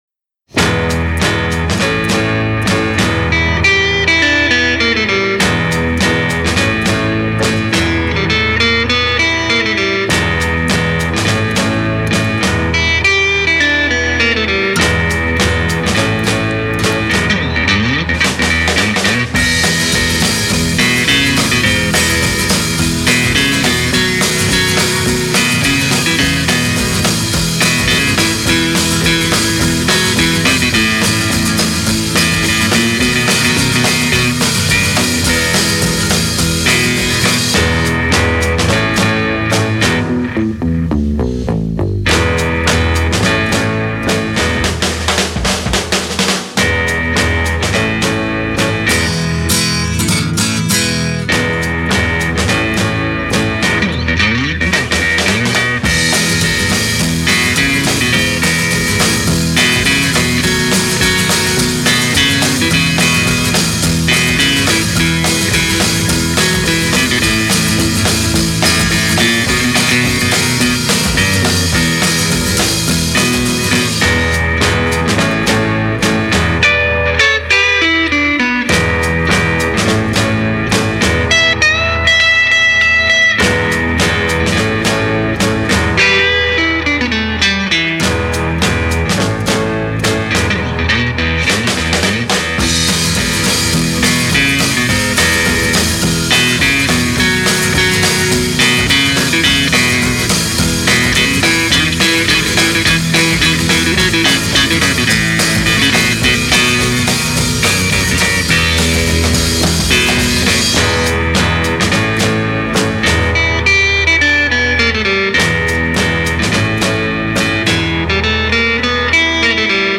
Original Mono